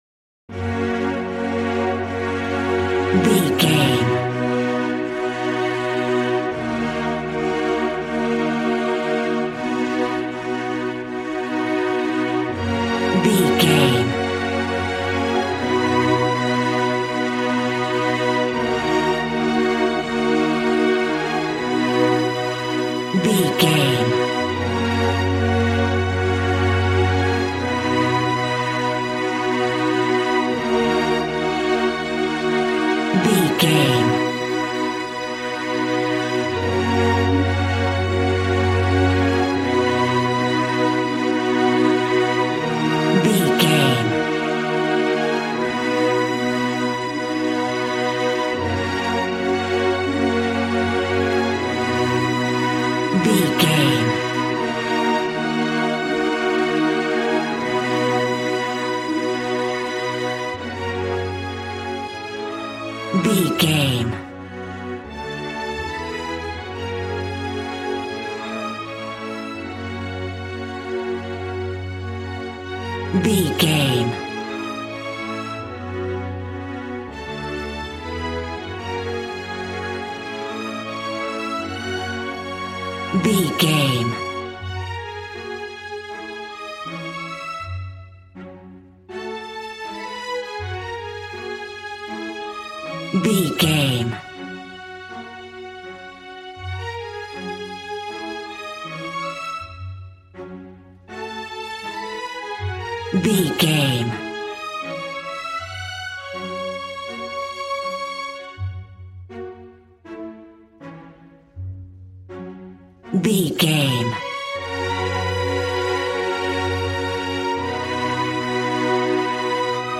Ionian/Major
G♭
regal
strings
brass